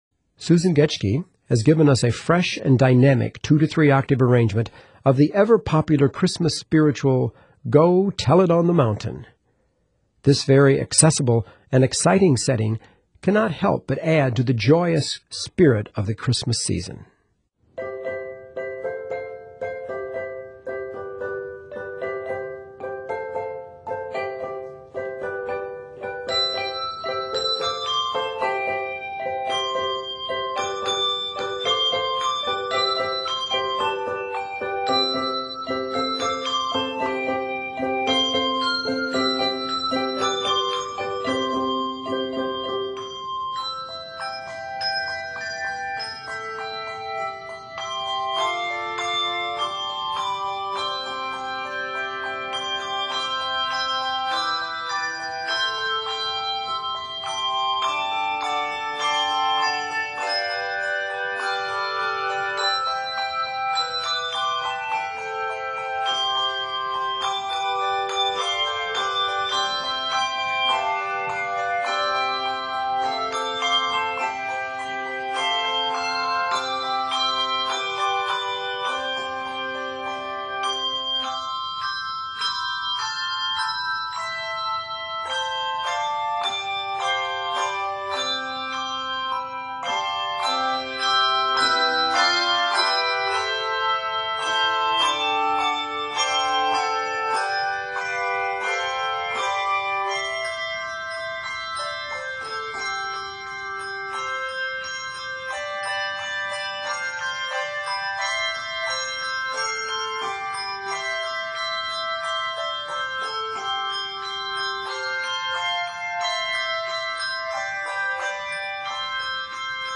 2-3 octave setting